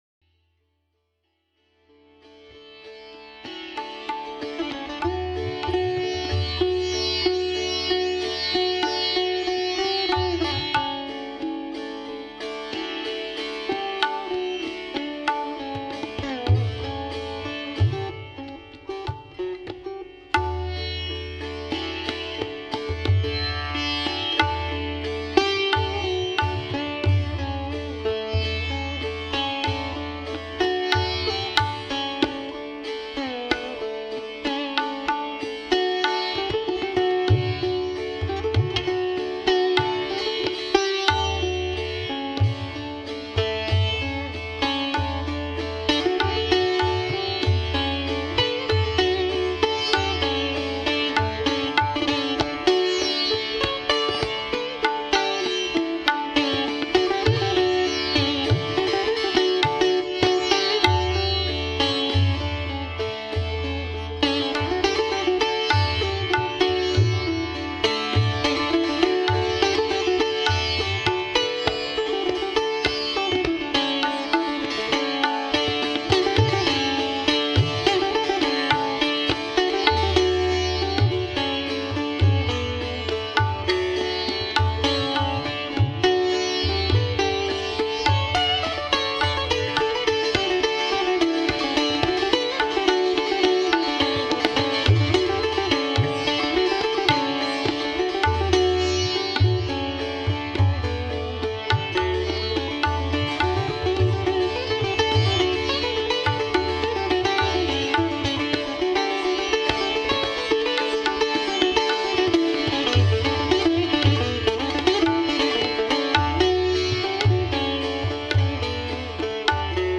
Indian Classical – SITAR